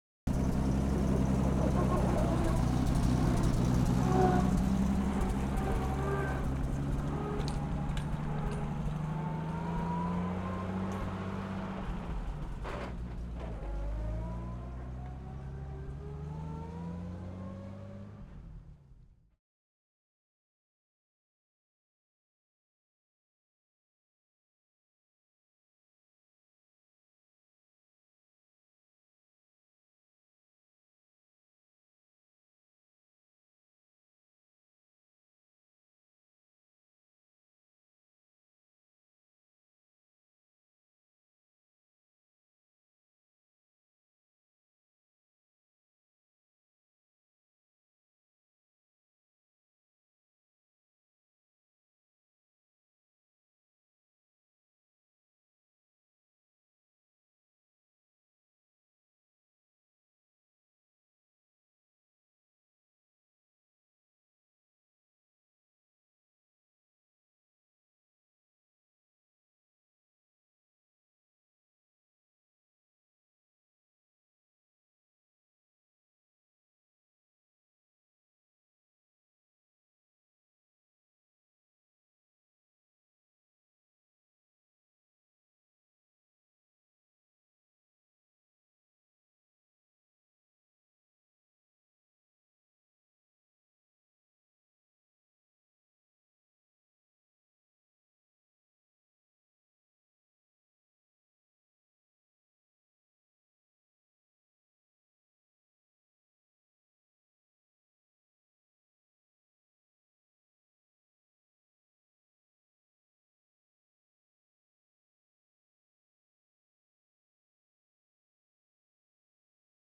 Lublin_51_t9_Ext_Medium_Drive_Various_M10.ogg